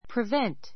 prevent A2 privént プ リ ヴェ ン ト 動詞 ❶ 妨 さまた げる, ～を～できないようにする Rain prevented the baseball game.